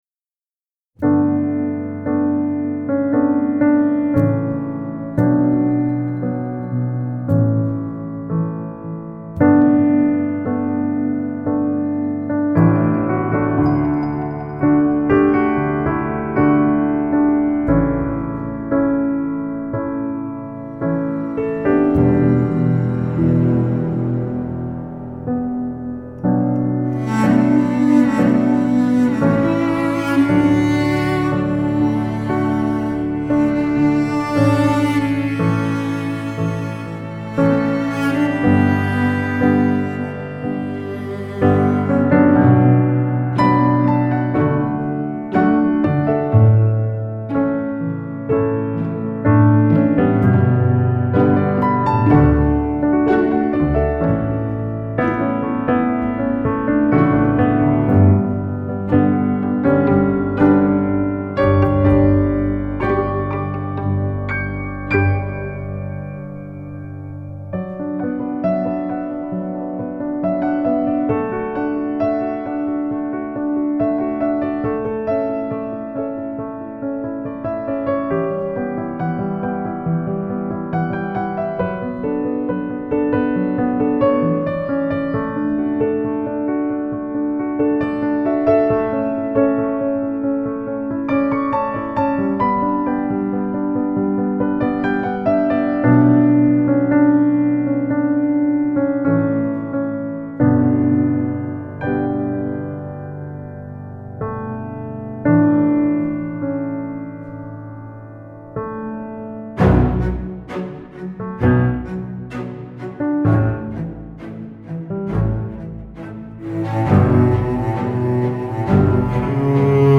آرامش بخش
Classical Crossover
پیانو